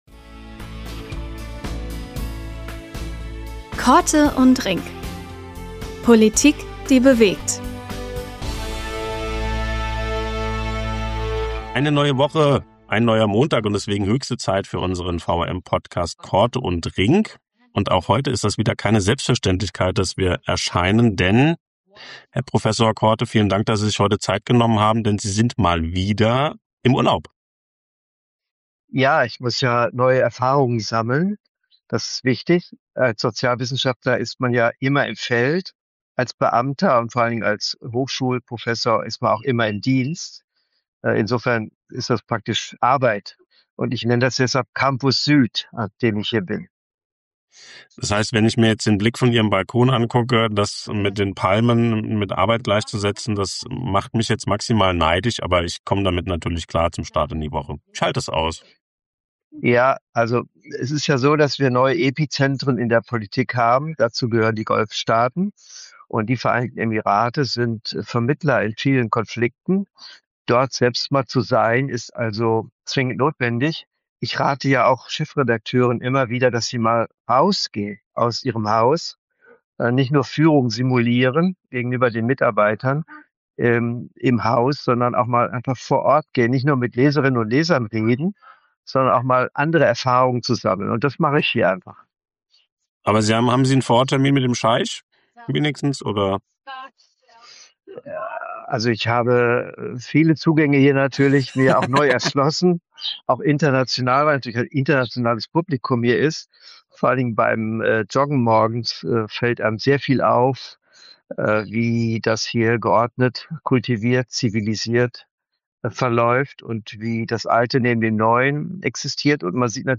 Unser Blick geht mal wieder über den deutschen Tellerrand hinaus, denn Prof. Dr. Karl-Rudolf Korte meldet sich in dieser Folge aus Abu Dhabi, einem Ort mit geopolitischer Bedeutung. Im Mittelpunkt der aktuellen Folge steht die Frage, wie Politik und Gesellschaft krisensensibler und überraschungsfester werden können.